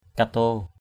/ka-to:/ (d.) cây cò ke = Grewia paniculata L. – Microcos tomentosa Sm. phaw kato f| k_t% súng tre bắn bằng trái cò ke.